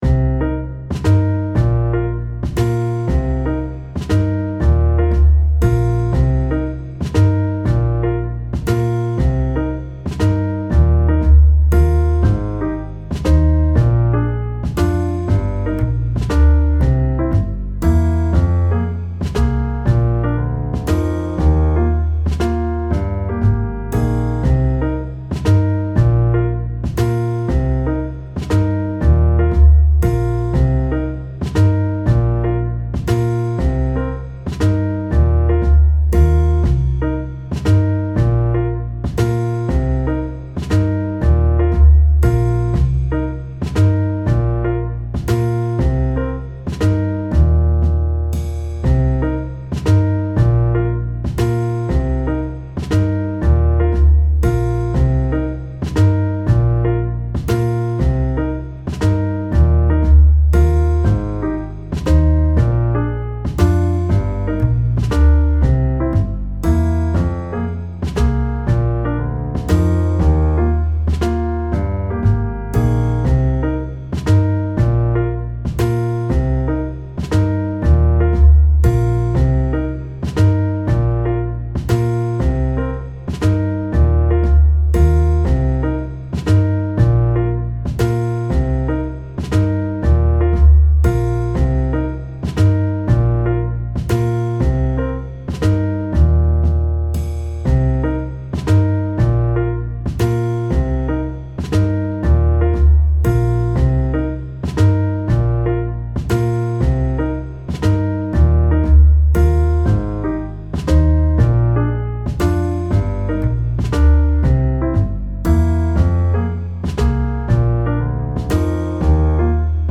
メロディを消しているので、より雰囲気だけのBGMが欲しい場合にうってつけです。
癒やし ドラマ ゆっくり 心地よい 作業用 ゆったり 穏やか 温かい 気持ち良い
ジャズ